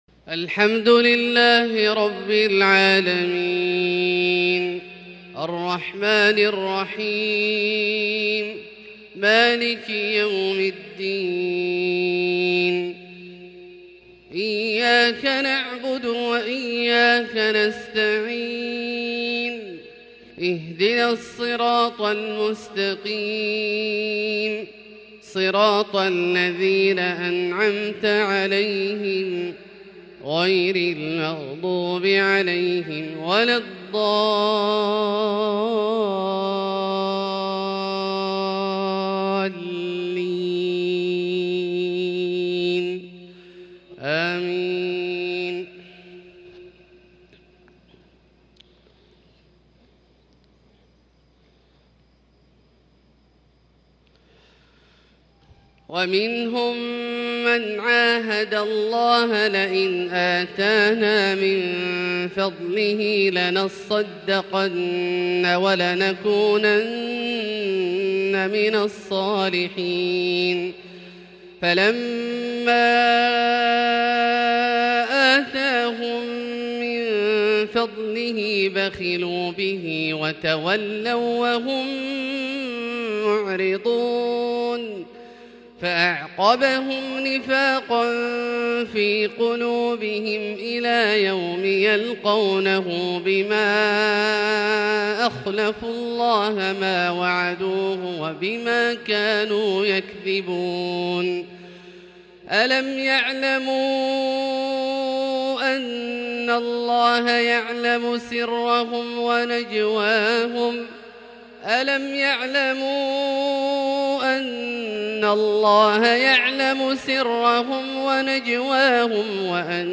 Fajr prayer from Surat At-Tawba | 4-2-2021 > H 1442 > Prayers - Abdullah Al-Juhani Recitations